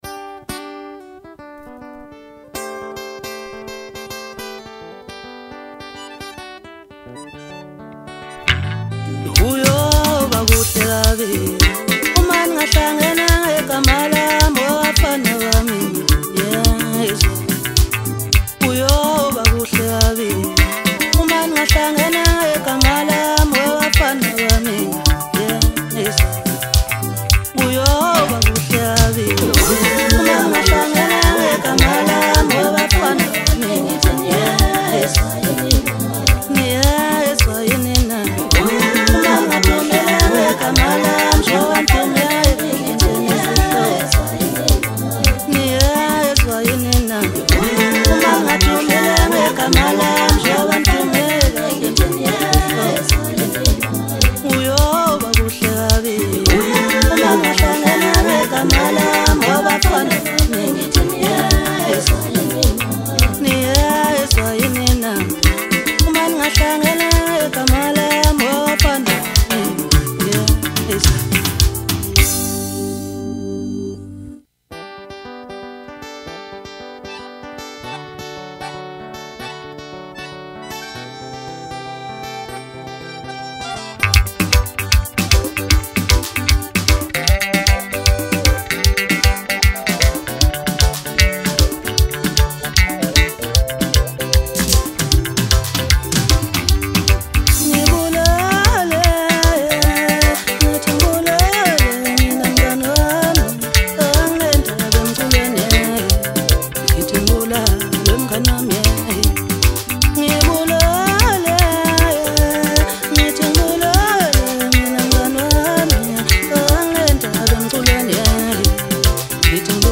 Home » Maskandi » Maskandi Music